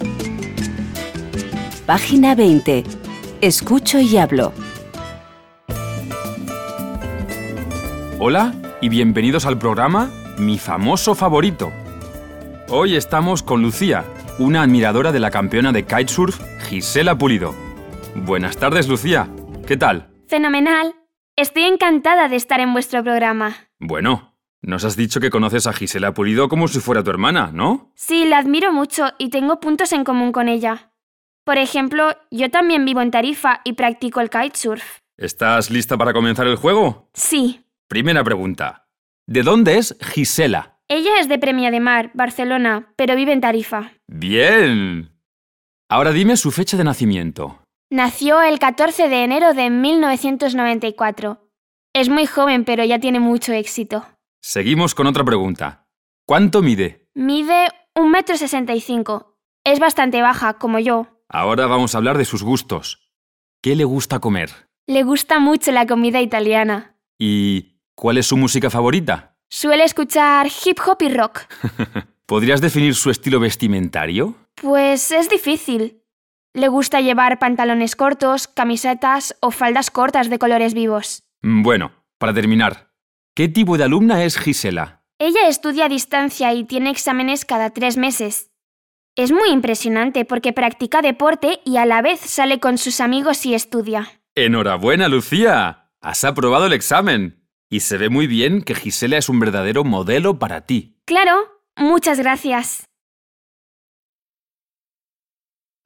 GISELA PULIDO, comprensión oral